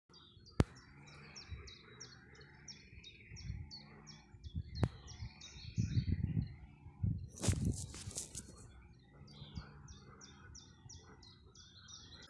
Čuņčiņš, Phylloscopus collybita
Ziņotāja saglabāts vietas nosaukumsBauskas nov Vecumnieku pag.
StatussDzied ligzdošanai piemērotā biotopā (D)